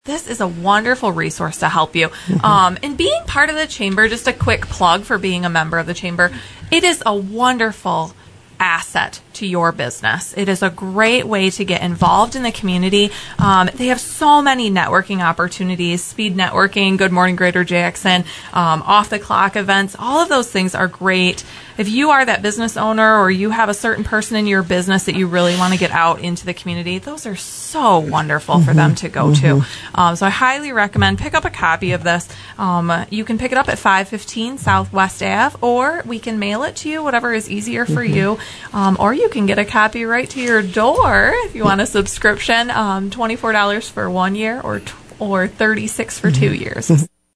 also visited the WKHM studio.